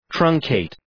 Προφορά
{‘trʌŋ,keıt}